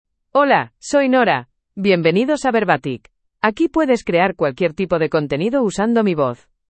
Nora — Female Spanish (Spain) AI Voice | TTS, Voice Cloning & Video | Verbatik AI
Nora is a female AI voice for Spanish (Spain).
Voice sample
Listen to Nora's female Spanish voice.
Nora delivers clear pronunciation with authentic Spain Spanish intonation, making your content sound professionally produced.